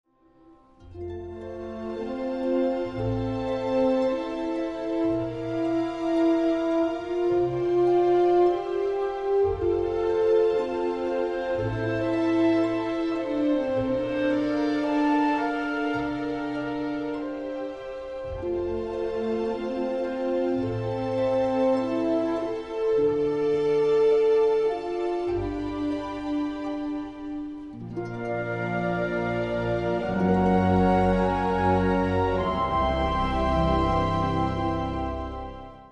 Den bredt malende symfoniske pensel
et særdeles smukt kærlighedstema